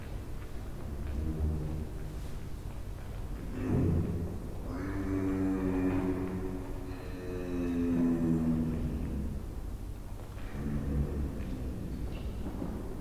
Голос жирафа в саванне